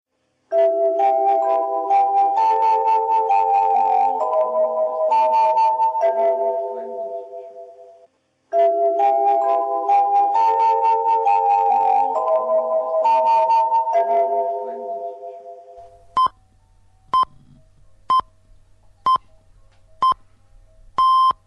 Звук радио Маяк с пиканием (СССР)